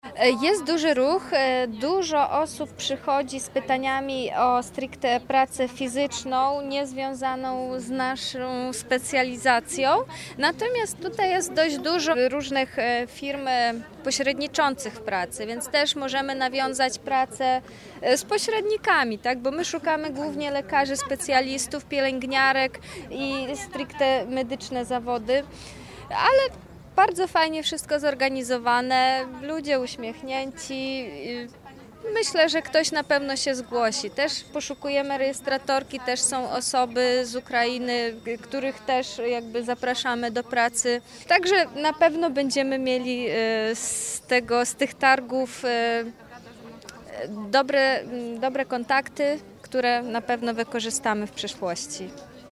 – Dużo osób jest zainteresowanych – mówi jedna z pracodawców.